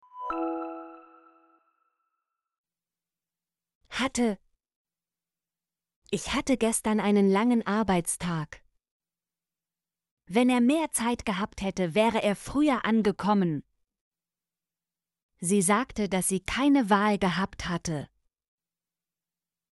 hatte - Example Sentences & Pronunciation, German Frequency List